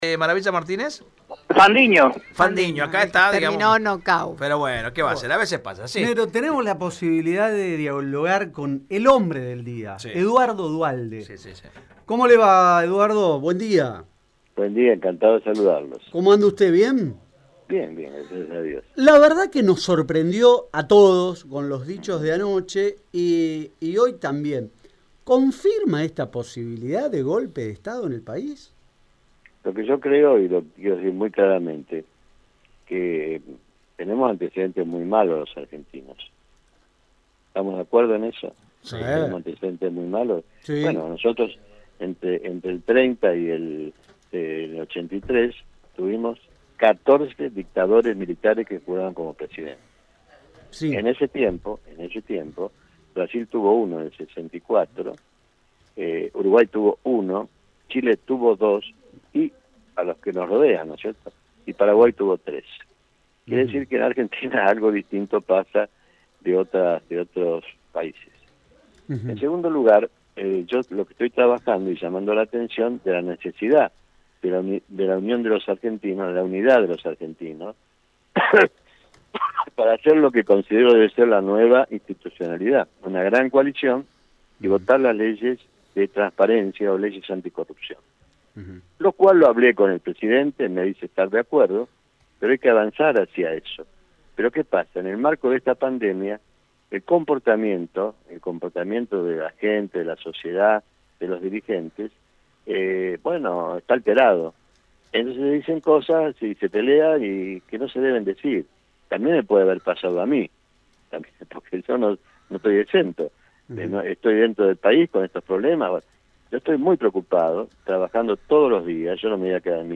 El expresidente de la Nación habló en exclusiva con Radio EME y admitió que aunque la frase suene muy fuerte, es lo que está pasando. Le pidió a Alberto Fernández que se desenoje y busque consensos.